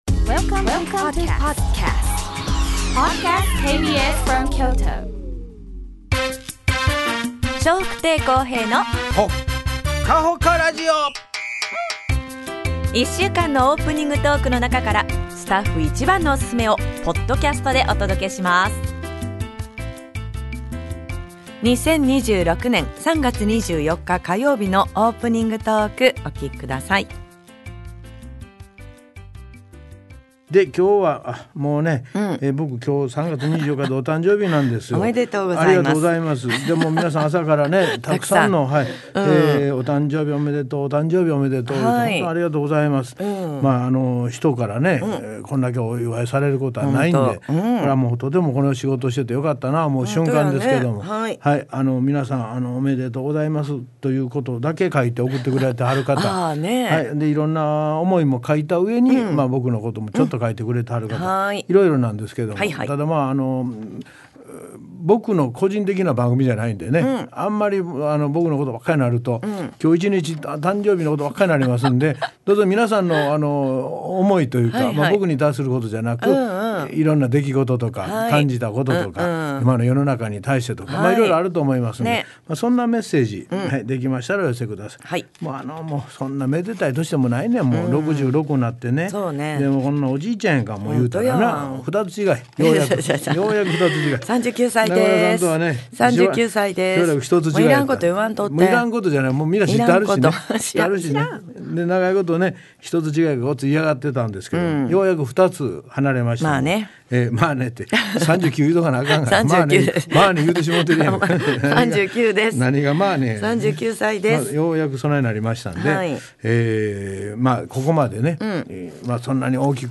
2026年3月24日のオープニングトーク